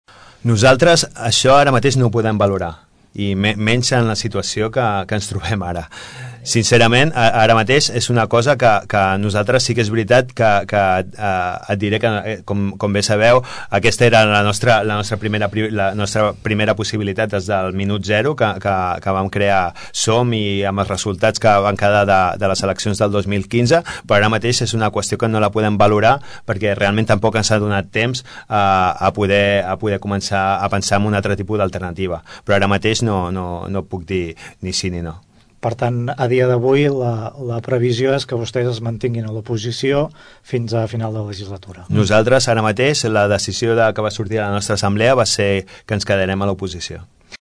Així ho explicava aquesta tarda, el regidor i portaveu de Som Tordera, Salvador Giralt en una entrevista a Ràdio Tordera.